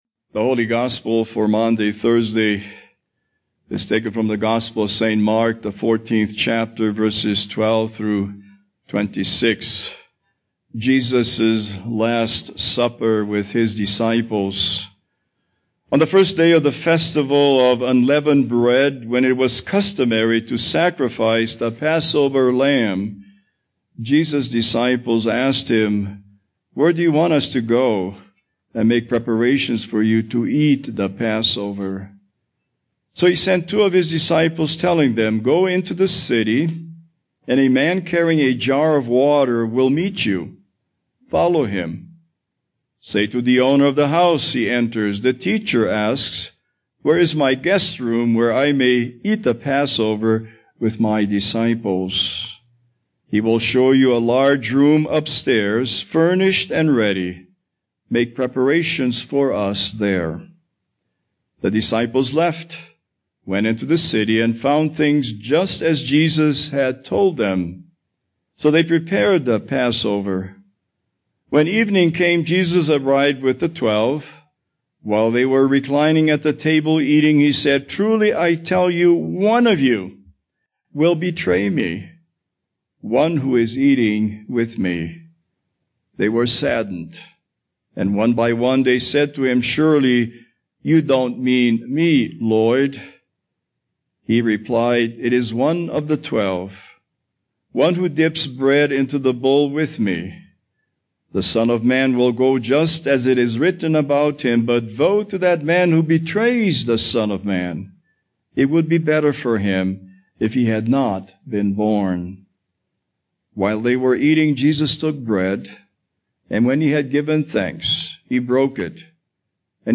Series: Holiday Sermons
Matthew 25:26 Service Type: Maundy Thursday « Hosanna to the Son of David!